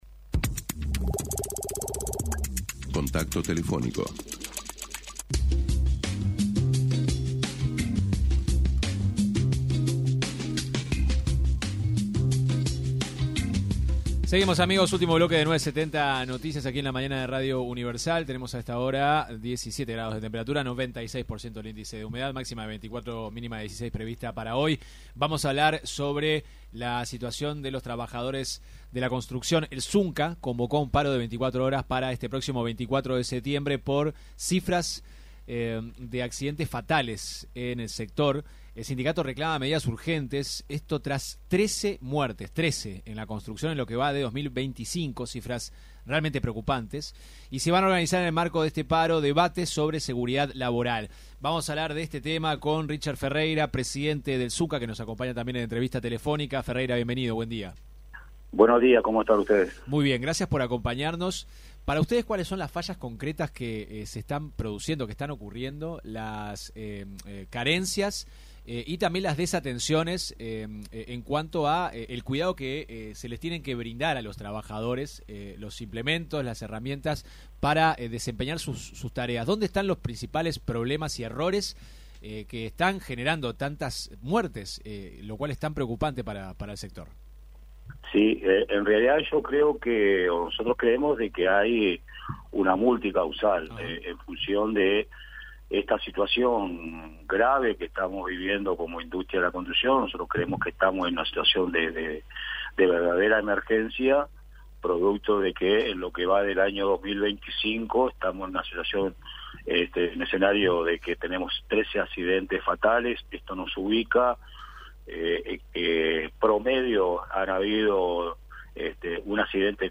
en entrevista con 970 Noticias